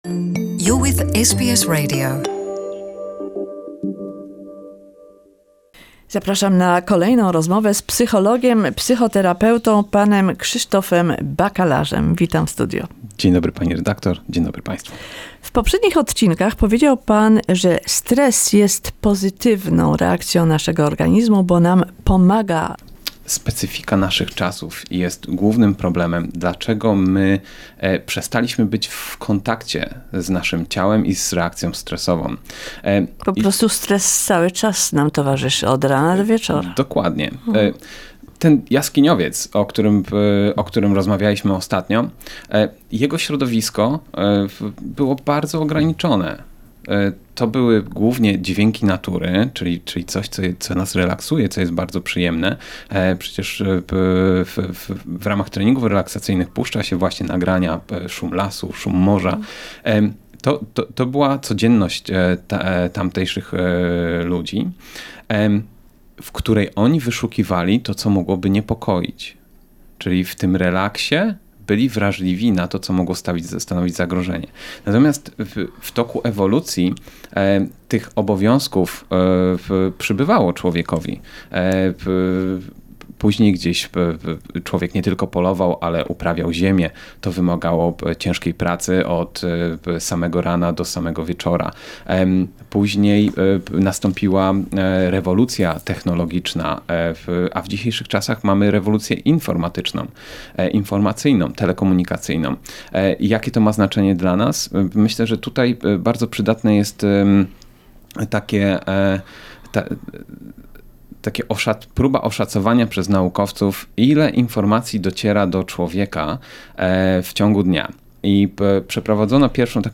Part 3 of the series of conversations on stress with psychotherapist